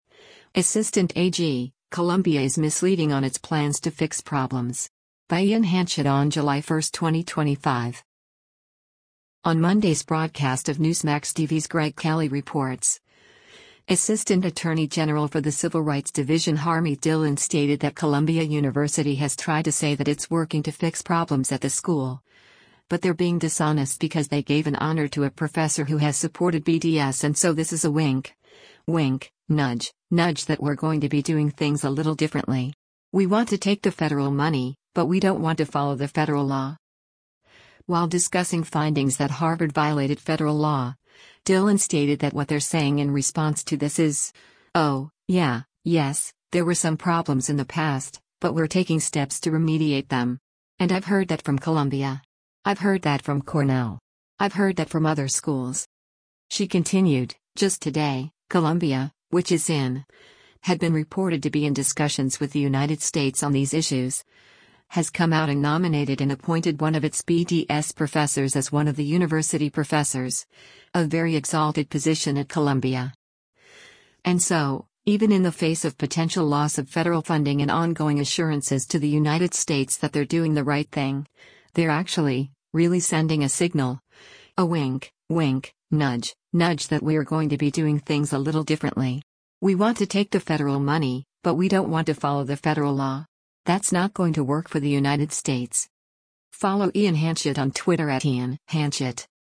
On Monday’s broadcast of Newsmax TV’s “Greg Kelly Reports,” Assistant Attorney General for the Civil Rights Division Harmeet Dhillon stated that Columbia University has tried to say that it’s working to fix problems at the school, but they’re being dishonest because they gave an honor to a professor who has supported BDS and so this is “a wink, wink, nudge, nudge that we’re going to be doing things a little differently. We want to take the federal money, but we don’t want to follow the federal law.”